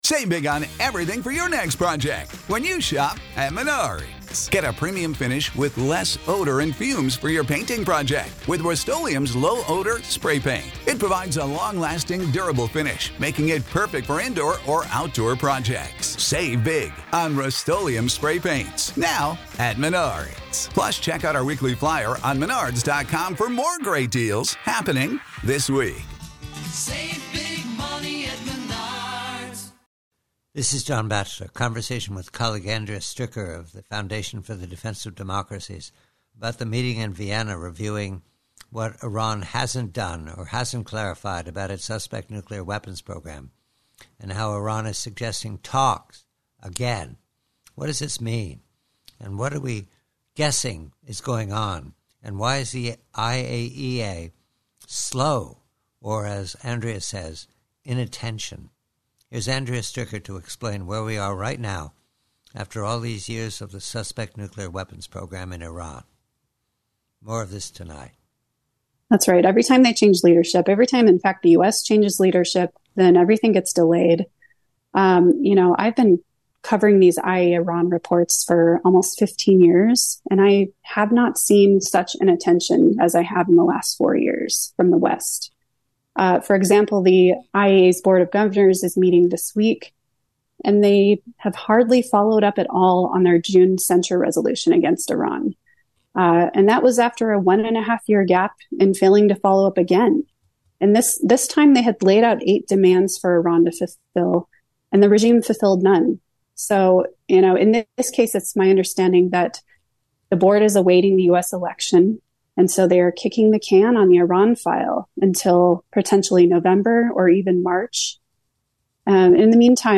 PREVIEW - IRAN: NUKES: Conversation